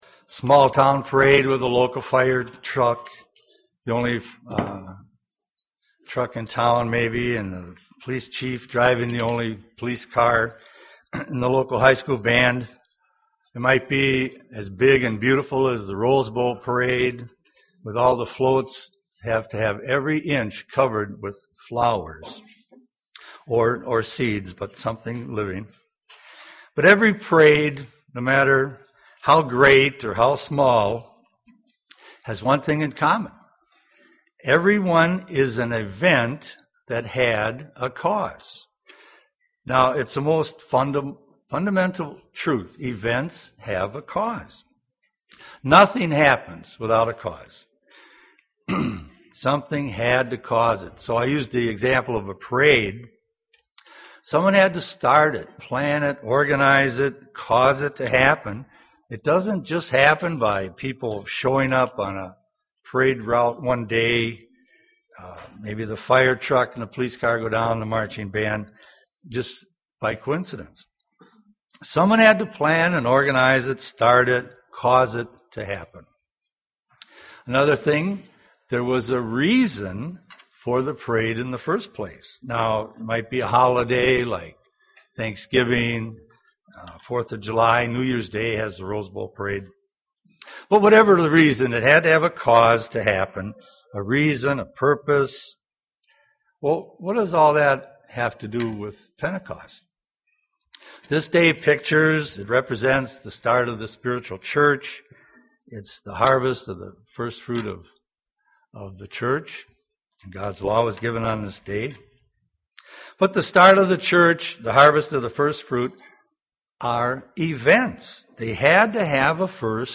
Given in Twin Cities, MN
UCG Sermon Studying the bible?